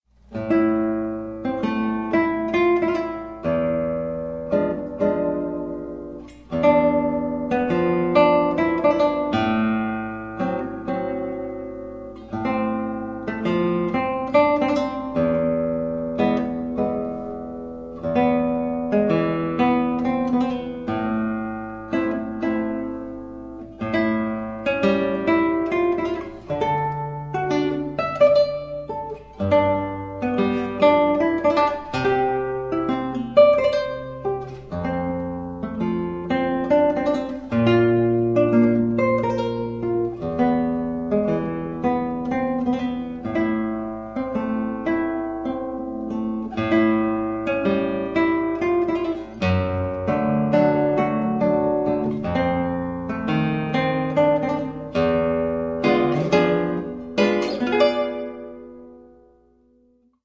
For Solo Guitar